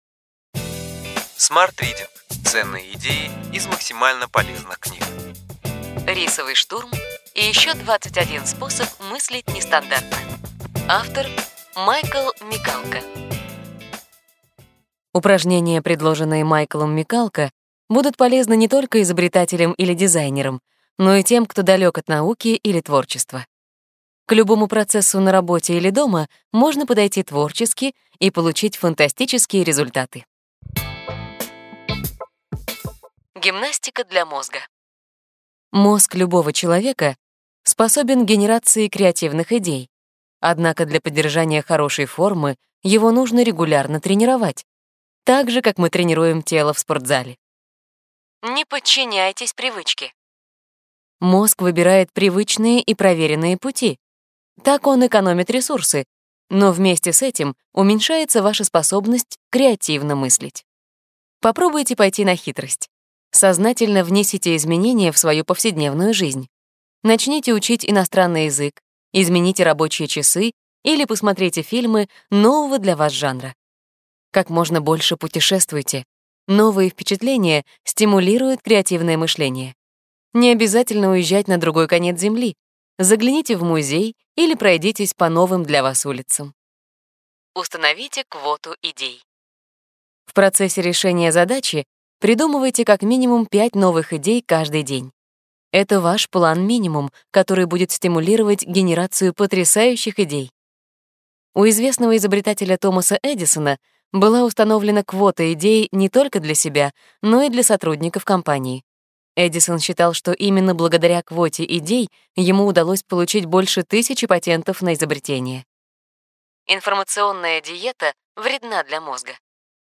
Аудиокнига Ключевые идеи книги: Рисовый штурм и еще 21 способ мыслить нестандартно.